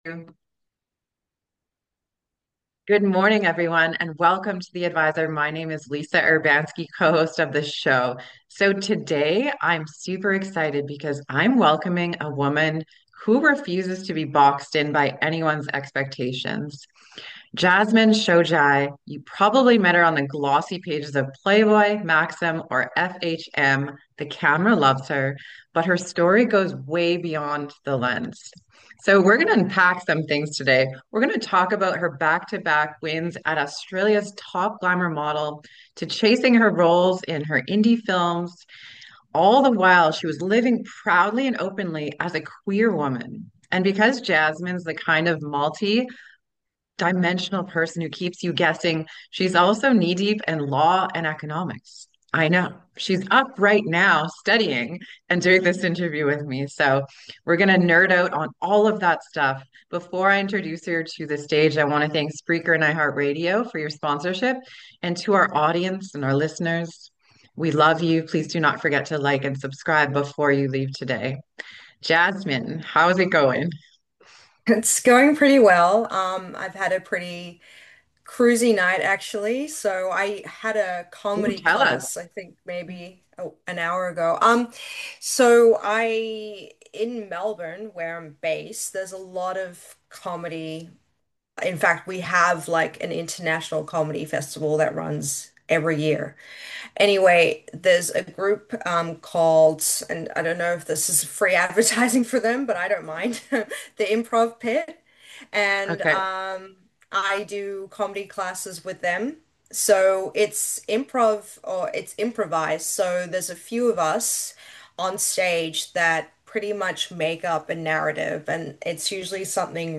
👉 Love insightful and life-changing interviews?